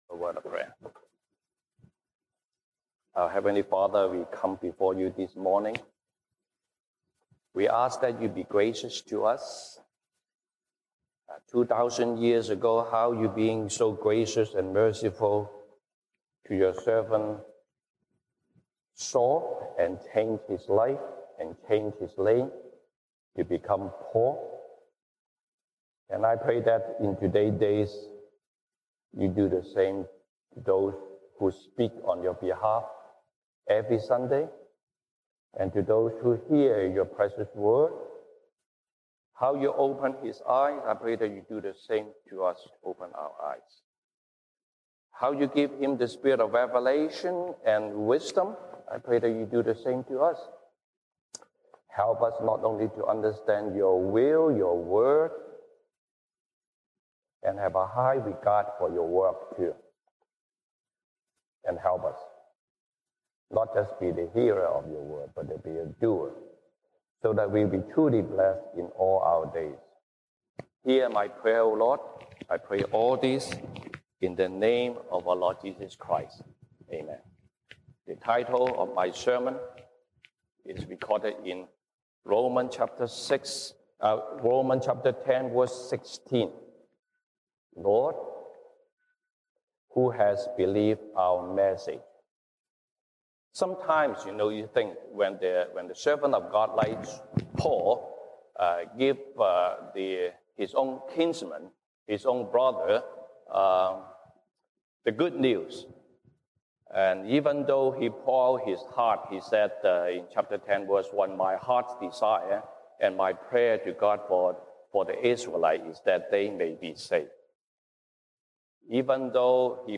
西堂證道 (英語) Sunday Service English: Lord, who has believed our message?
Romans Passage: 羅馬書 Romans 10:1-19 Service Type: 西堂證道 (英語) Sunday Service English Topics